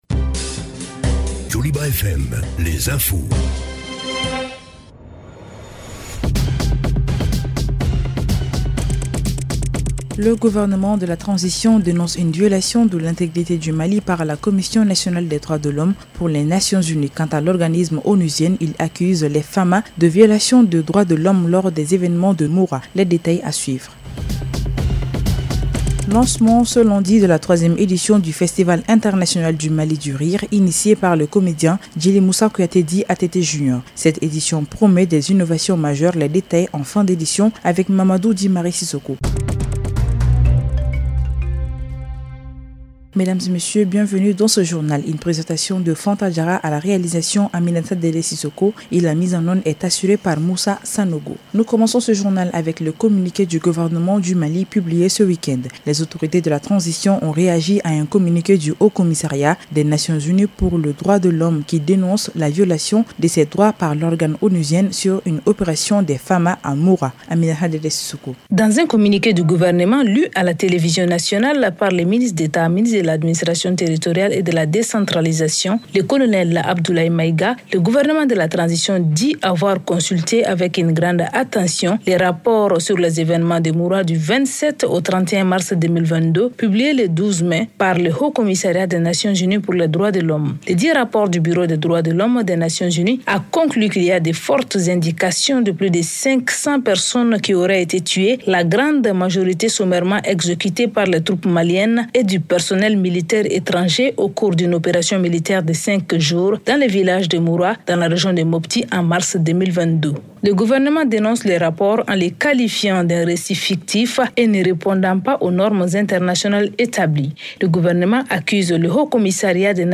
Écoutez votre journal en français sur Joliba FM.